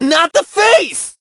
hotshot_hurt_02.ogg